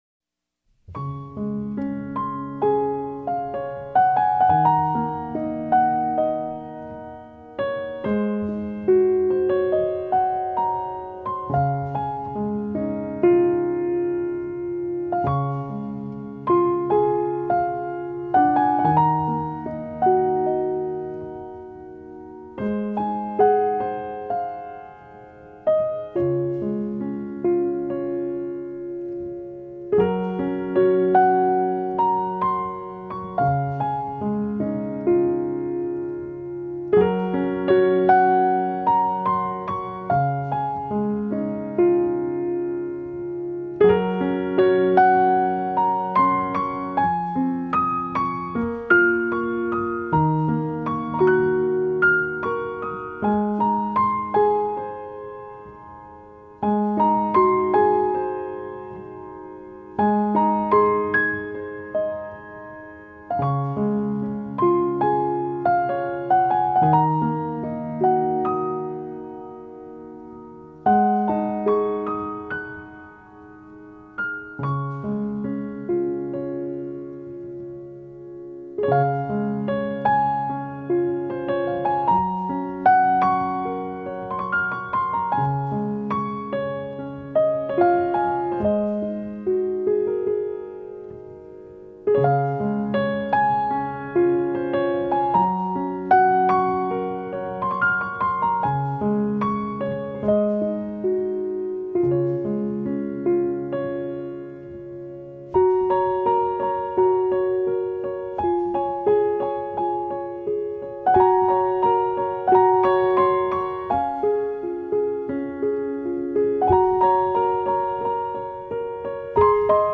鋼琴
部分曲子加入弦樂、吉他、手風琴等樂器，呈現更豐富的音樂氛圍。
用最溫柔、平和的曲調表現出來。